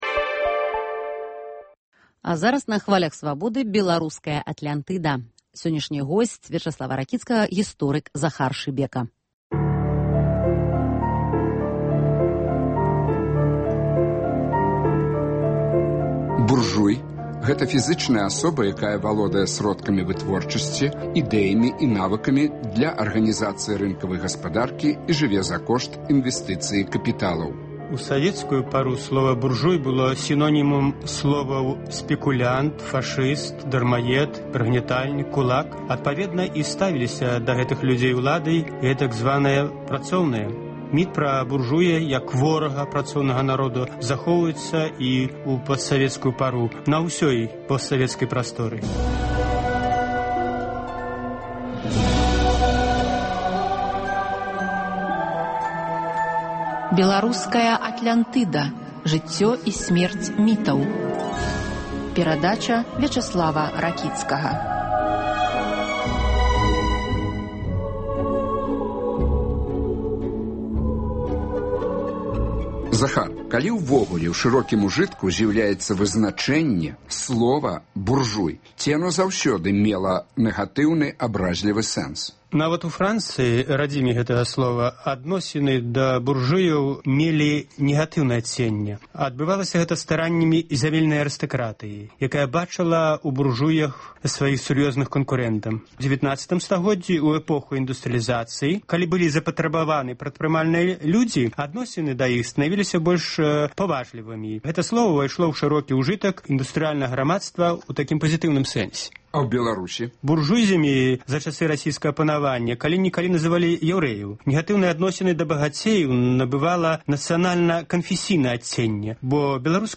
гутарыць зь гісторыкам